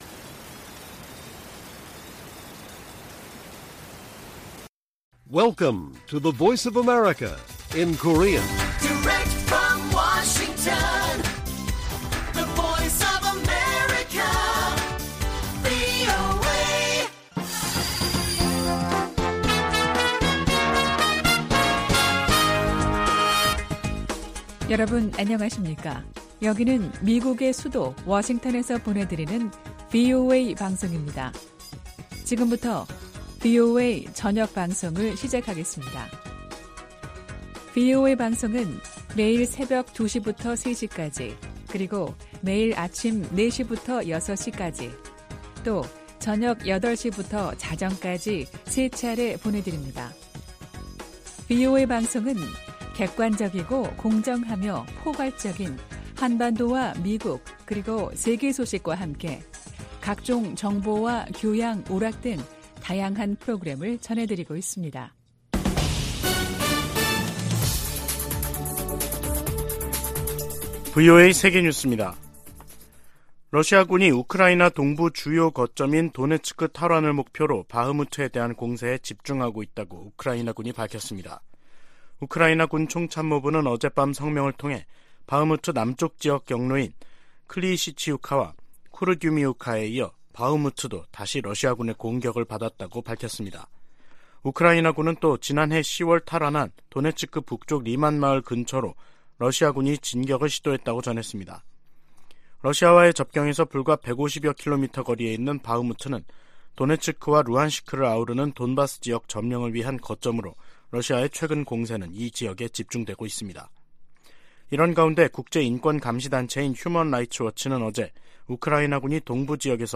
VOA 한국어 간판 뉴스 프로그램 '뉴스 투데이', 2023년 2월 1일 1부 방송입니다. 오는 3일 워싱턴에서 열릴 미-한 외교장관 회담에서는 북한의 도발 행위를 억제하는 중국의 역할을 끌어내기 위한 공조 외교를 펼 것이라는 관측이 나오고 있습니다. 미국 국무부가 한국에서 독자 핵 개발 지지 여론이 확대되는 것과 관련해, 핵무장 의지가 없다는 윤석열 정부의 약속을 상기시켰습니다.